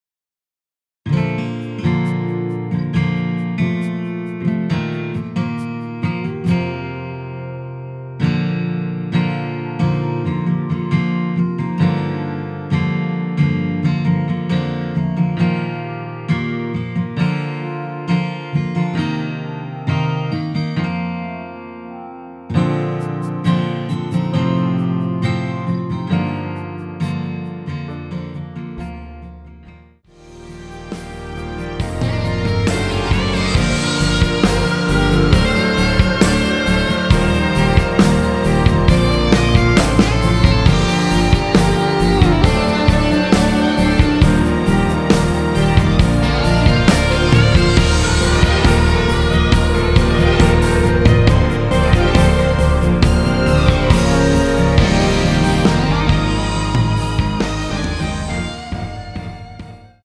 원키에서(-3)내린 MR입니다.
Bb
앞부분30초, 뒷부분30초씩 편집해서 올려 드리고 있습니다.
중간에 음이 끈어지고 다시 나오는 이유는